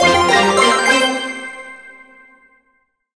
quest_complete_01.ogg